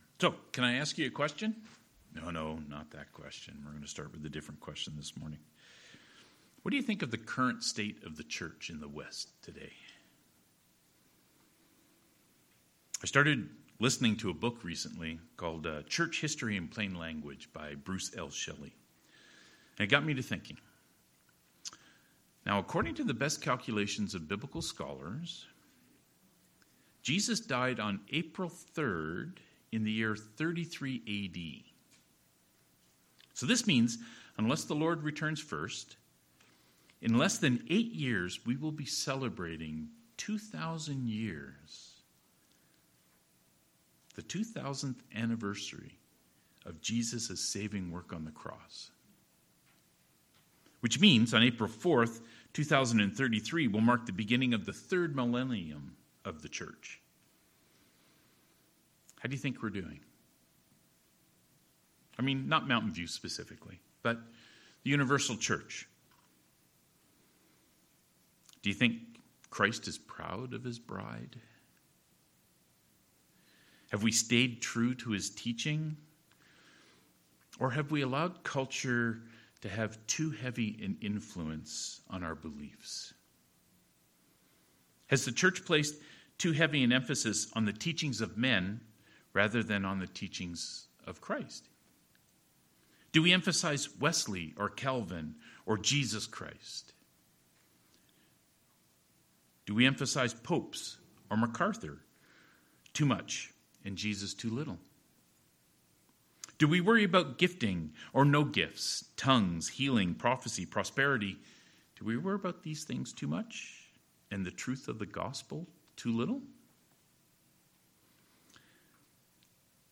31-47 Service Type: Sermons « You will die in your sin…